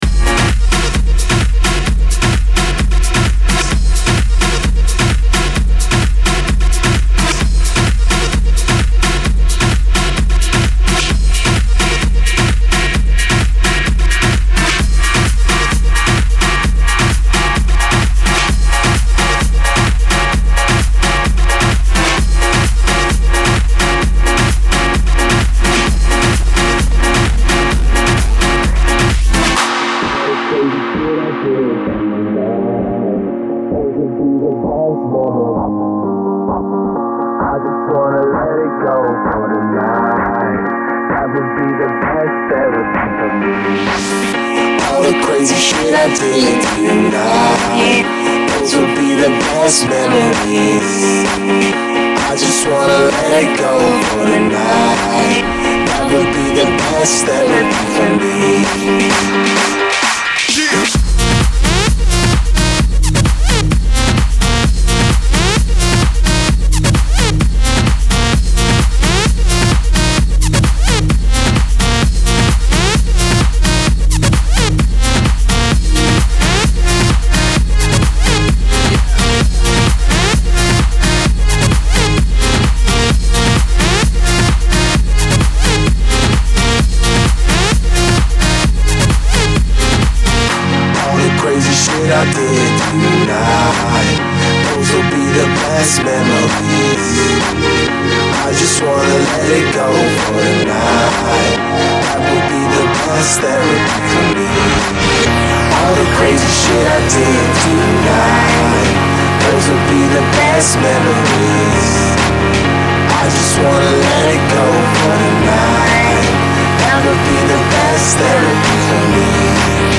And nevertheless this is a sweet remix 😀